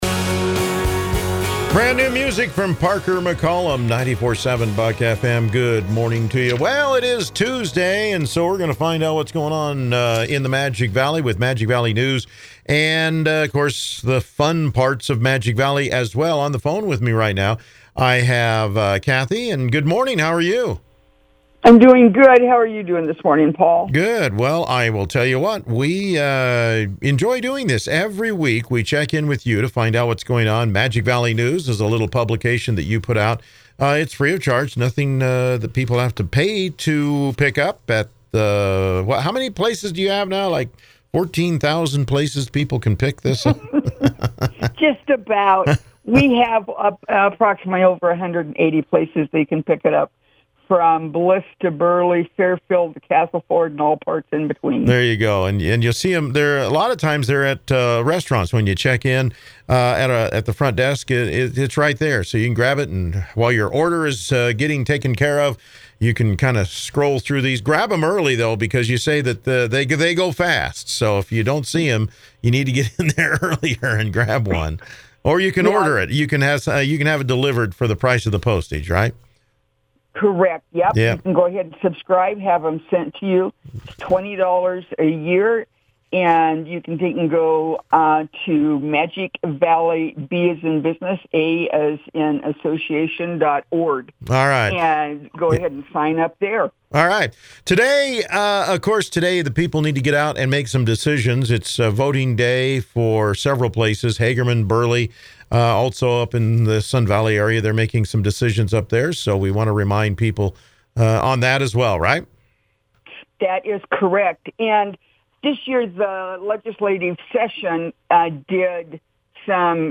Radio Chats